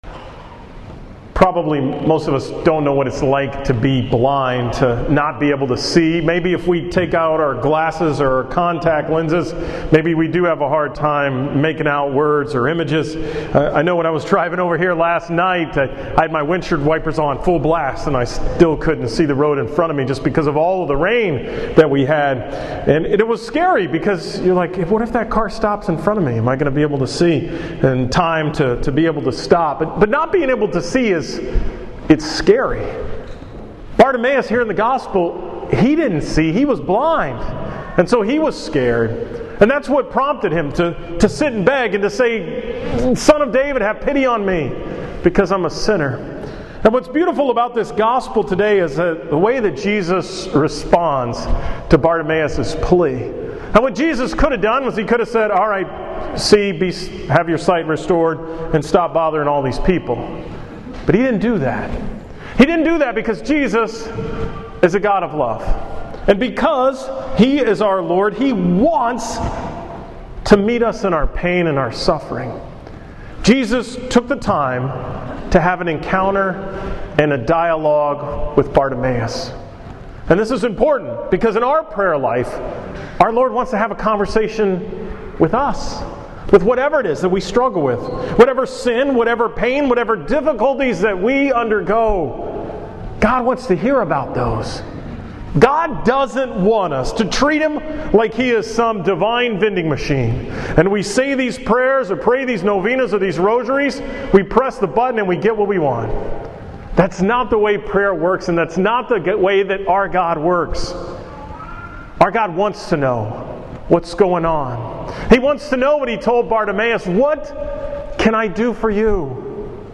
From the 11 am Mass at St. Laurence on October 25, 2015